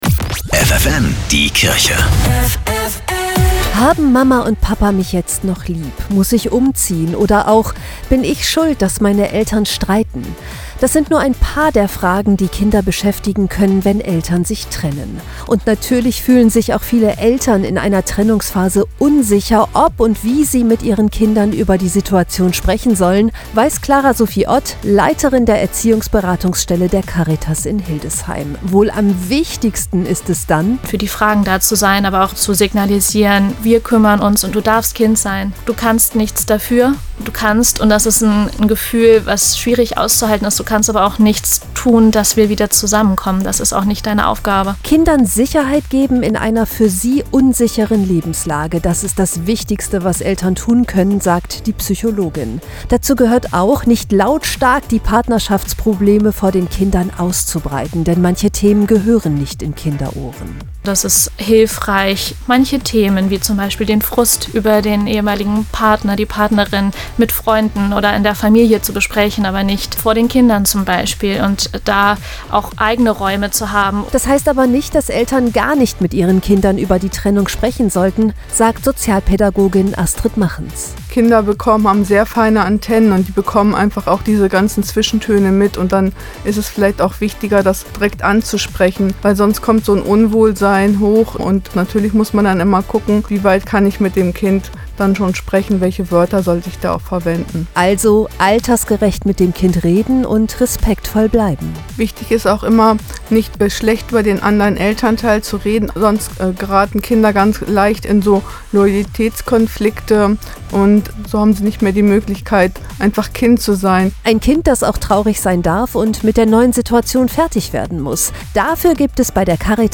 Radiobeiträge: